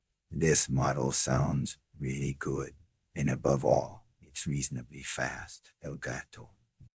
V2_Denoised_BEST/generated_example.wav
death_from_puss_and_boots_xtts